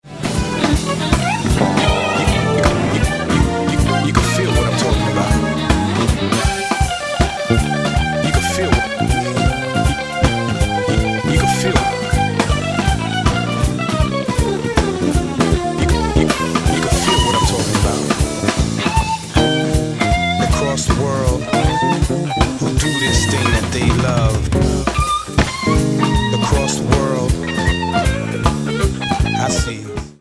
guitar: Agostin Z24